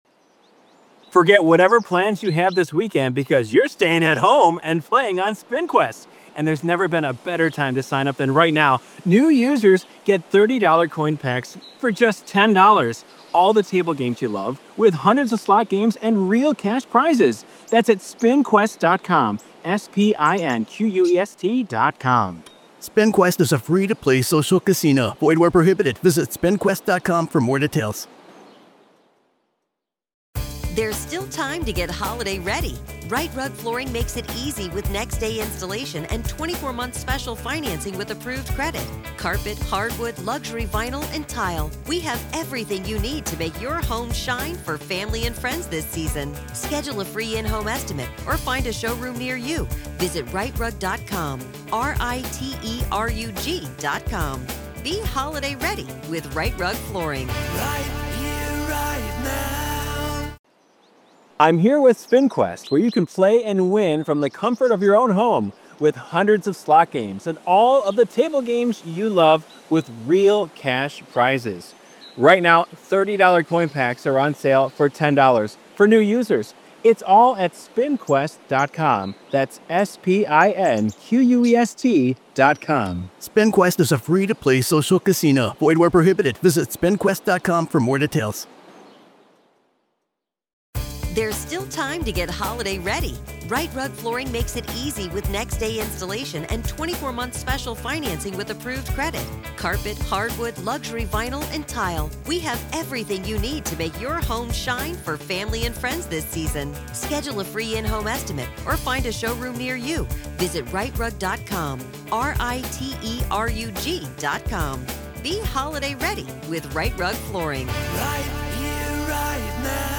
Hidden Killers Live! Daily True Crime News & Breakdowns